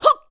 FSA_Link_SwordSlash49.45 KBMono, 16 KHz
FSA_Link_SwordSlash4.wav